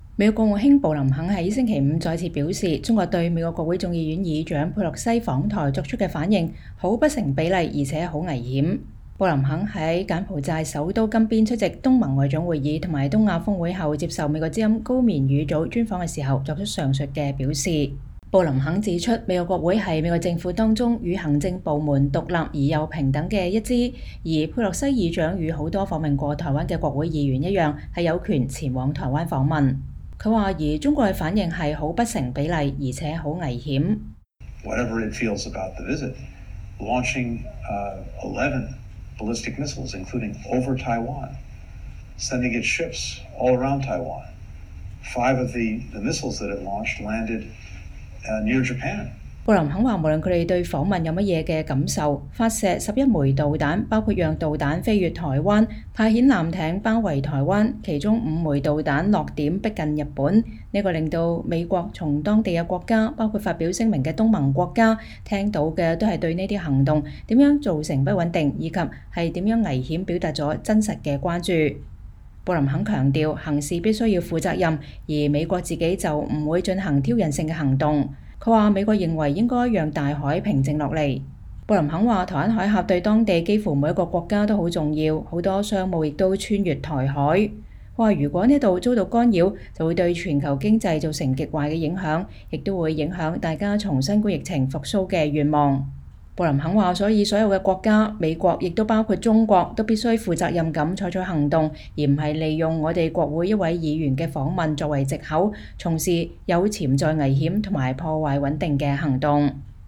布林肯接受美國之音專訪強調北京在台海軍事行動“很不成比例且很危險”